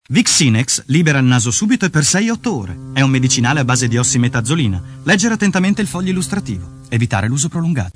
Demo Audio Pubblicità Voiceover